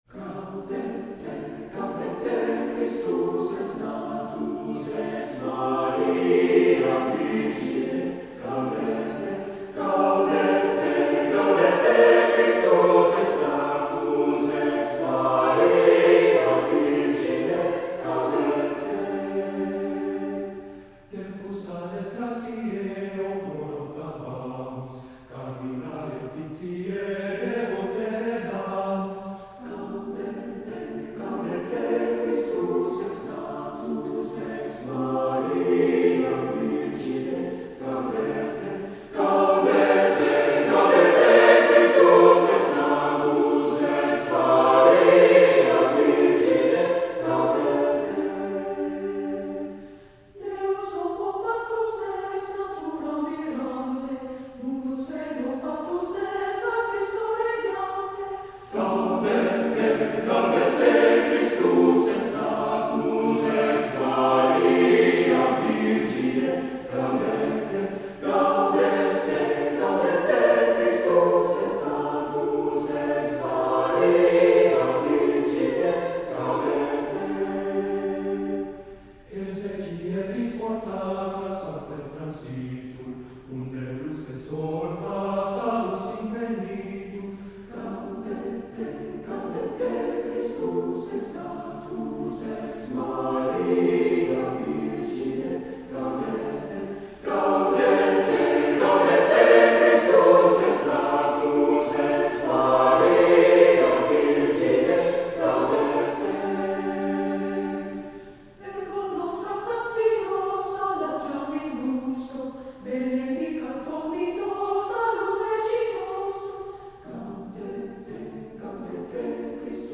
{“Gaudete” recorded by the Blessed Sacrament Choir}